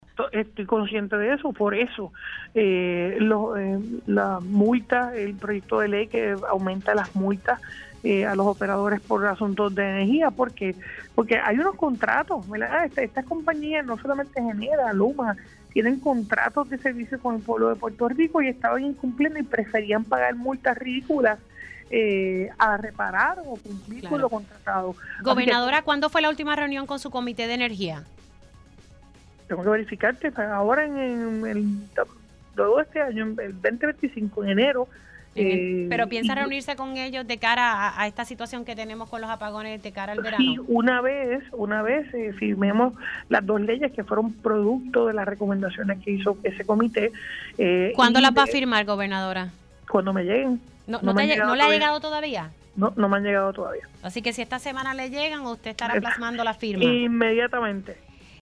122-JENNIFFER-GONZALEZ-GOBERNADORA-TAN-PRONTO-LLEGUEN-MEDIDAS-DE-MULTAS-A-LUMA-Y-GENERA-LO-CONVERTIRA-EN-LEY.mp3